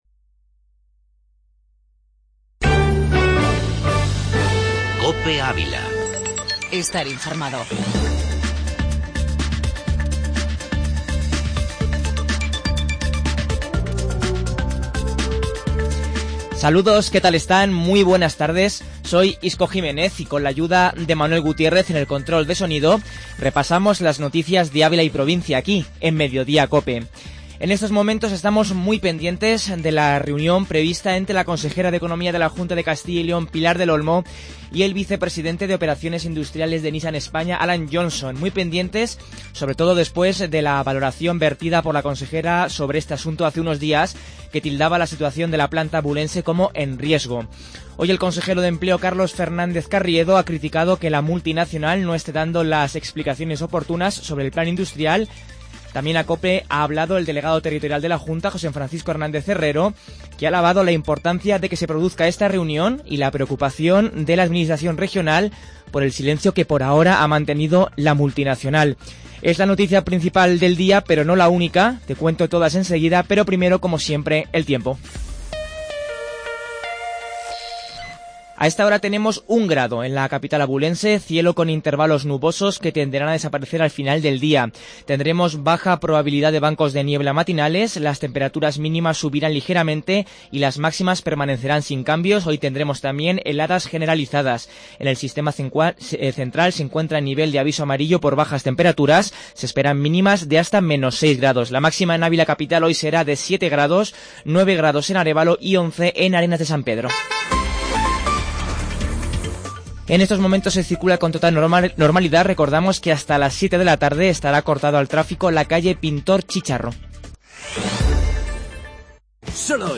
Informativo Mediodía Cope en Avila